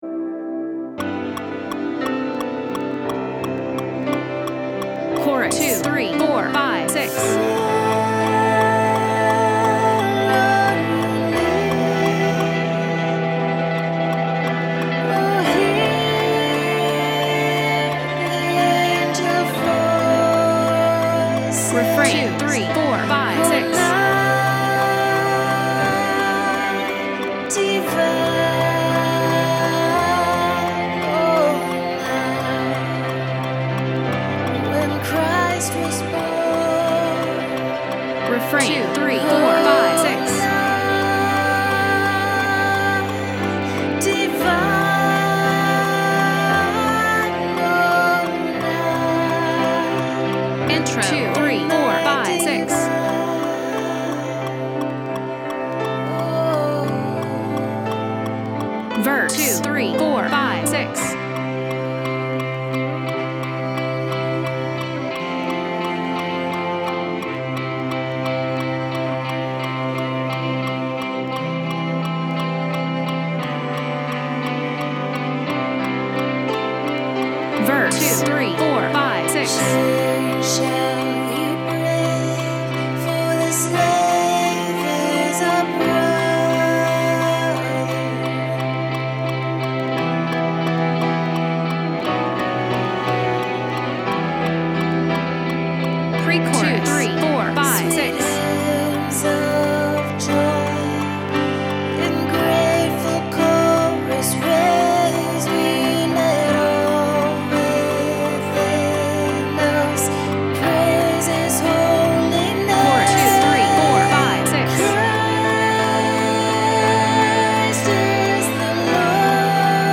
Harmony:
O-Holy-Night-Choir.mp3